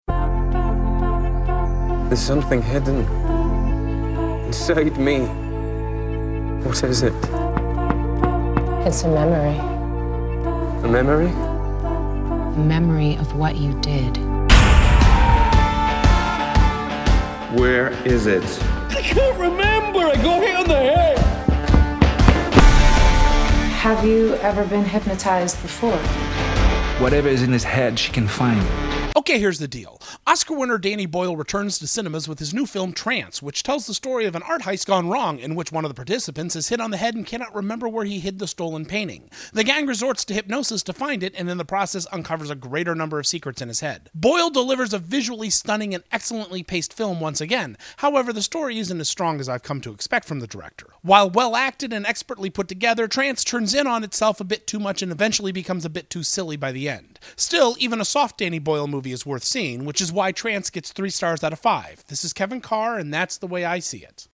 Trance” Movie Review